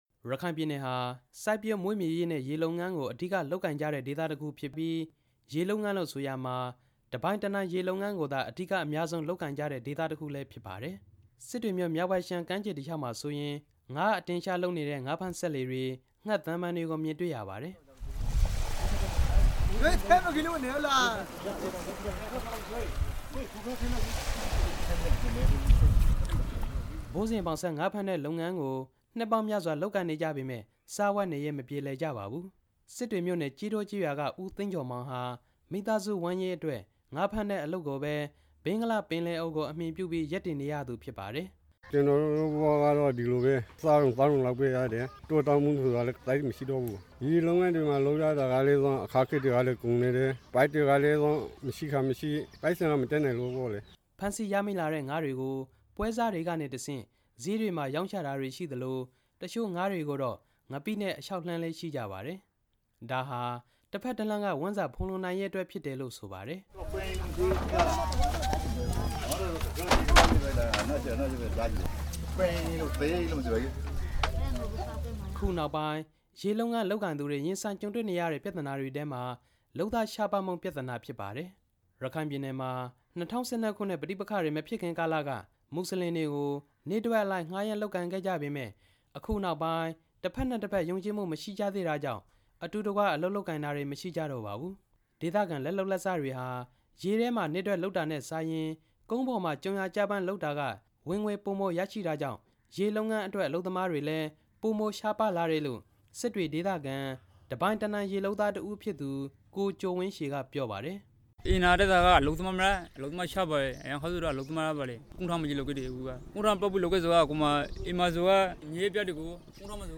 စစ်တွေတဝိုက်က ရေလုပ်သားတွေ ကမ်းနီးမှာငါးဖမ်းဖို့ ခက်ခဲနေတဲ့ အကြောင်းစုစည်း တင်ပြချက်